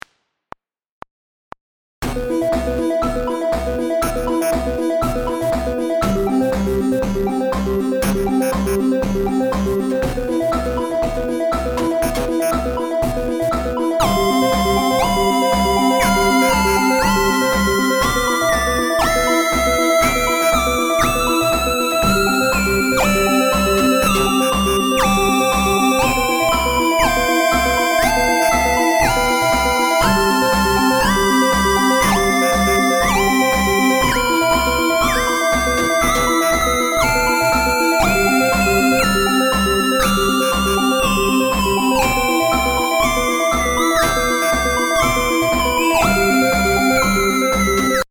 bleeps.mp3